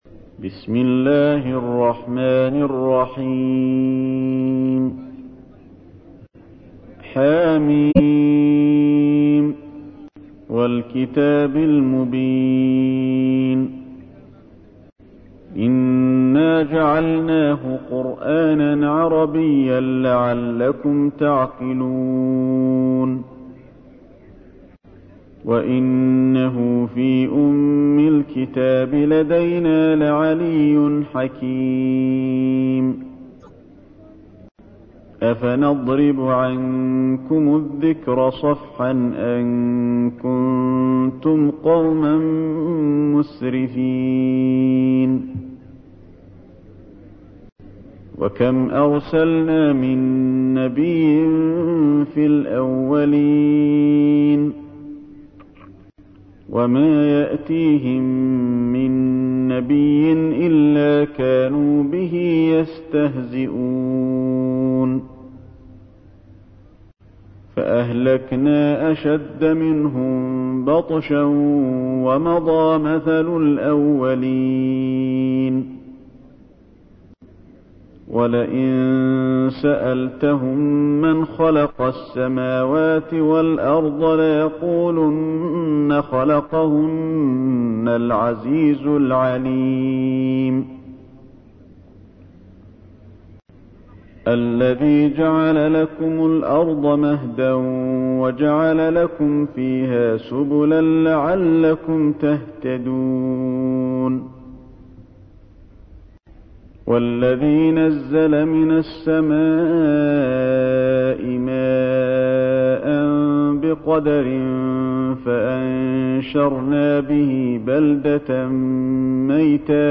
تحميل : 43. سورة الزخرف / القارئ علي الحذيفي / القرآن الكريم / موقع يا حسين